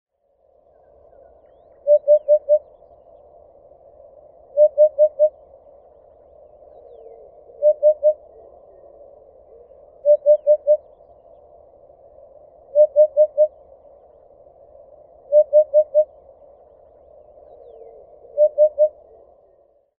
Vogelwerkgroep Meijendel - VWG-Meijendel - Zang en andere geluiden van in Meijendel broedende soorten